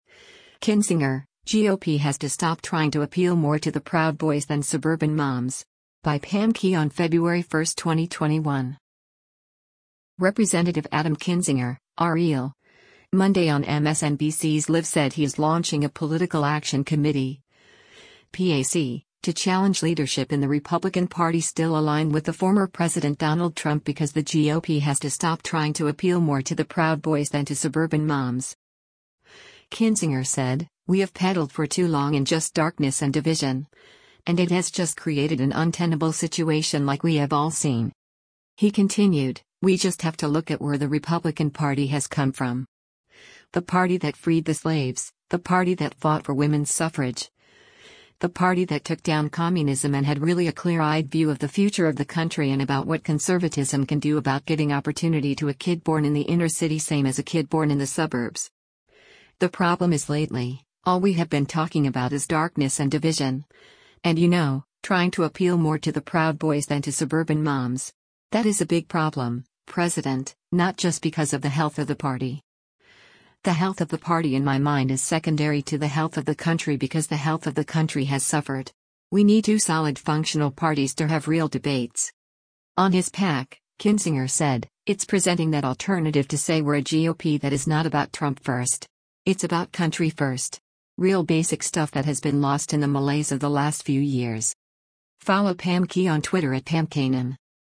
Representative Adam Kinzinger (R-IL) Monday on MSNBC’s “Live” said he is launching a political action committee (PAC) to challenge leadership in the Republican Party still aligned with the former president Donald Trump because the GOP has to stop “trying to appeal more to the Proud Boys than to suburban moms.”